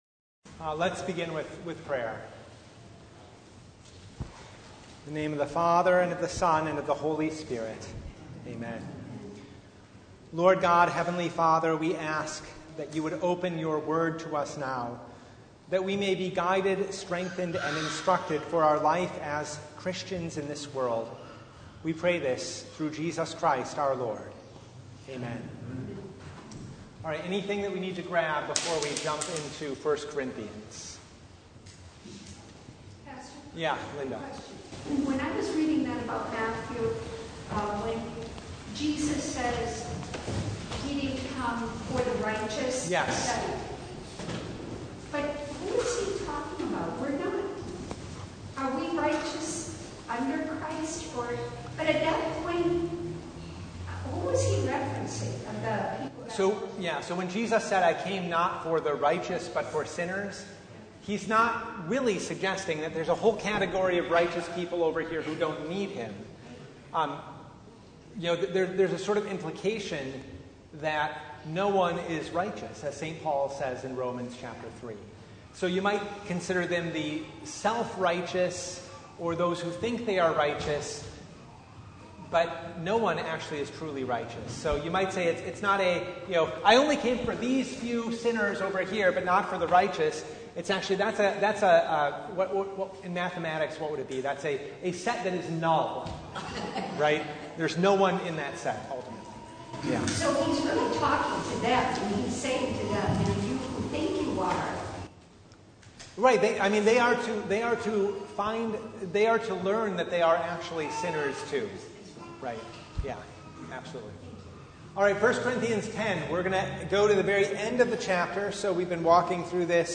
1 Corinthians 10:31-11:16 Service Type: Bible Hour Topics: Bible Study